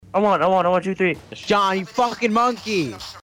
Shots